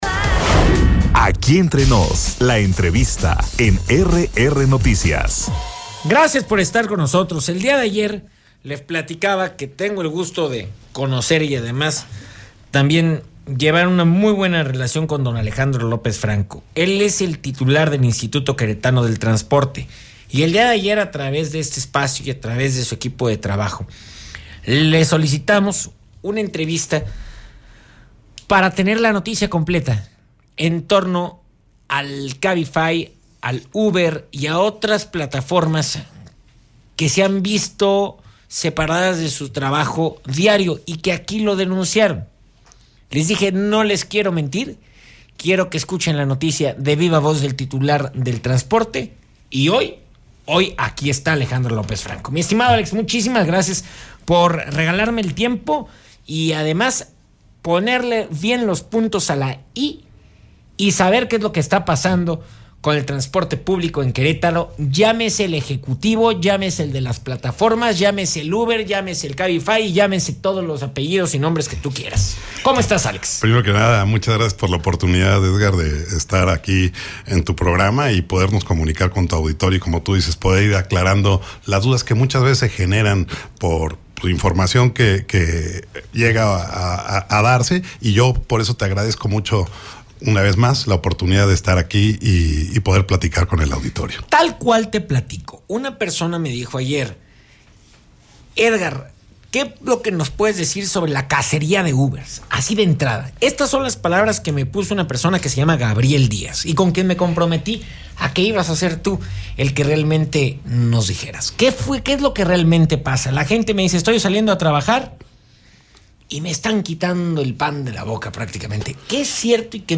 ENTREVISTA-ALEJANDRO-LOPEZ-FRANCO-TITULAR-DEL-IQT.mp3